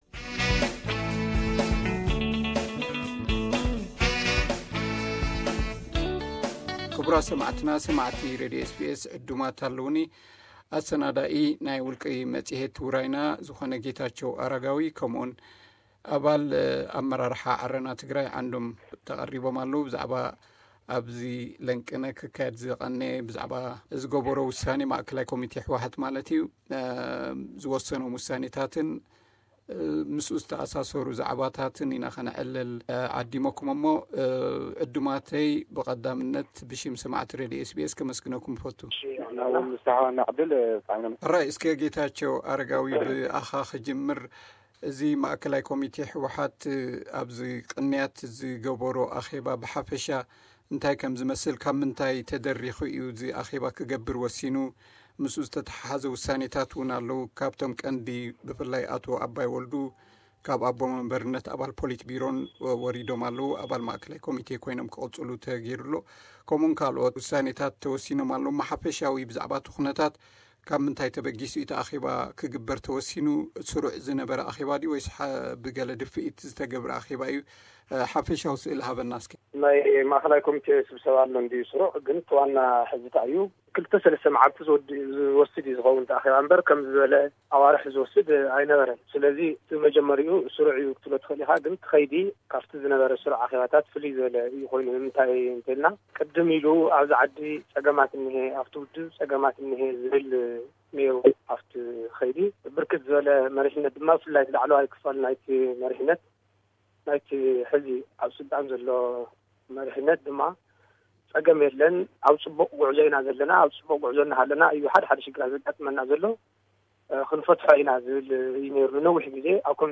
SBS Tigrinya Interview: TPLF shuffles its leaders